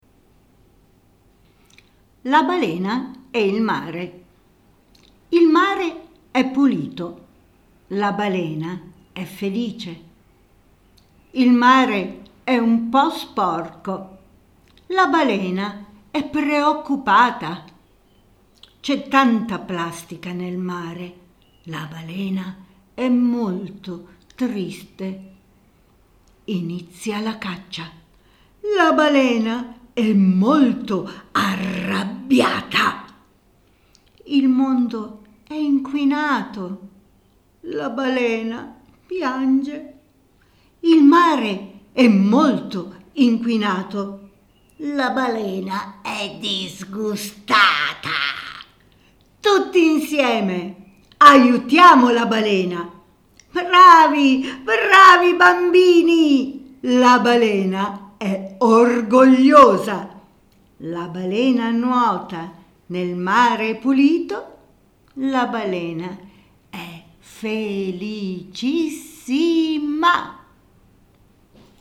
Come ogni libro MILLEragazzi, dal QRcode si accede alla lettura ad alta voce del testo.